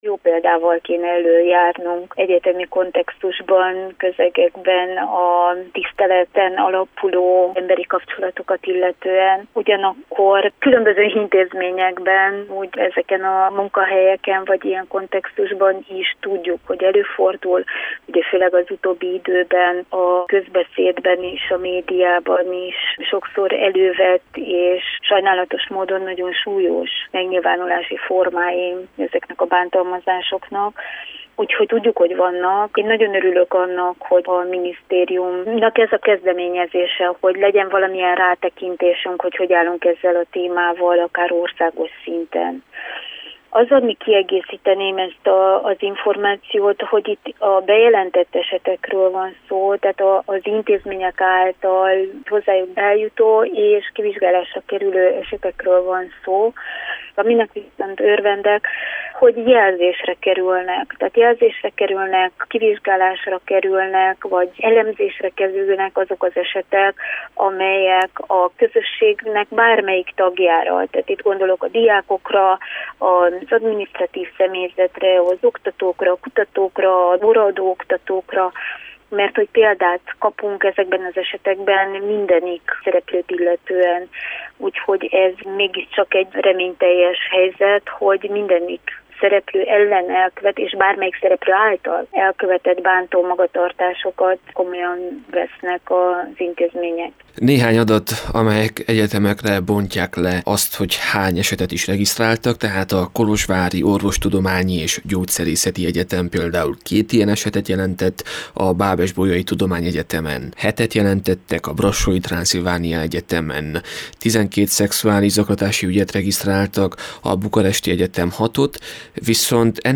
Riporter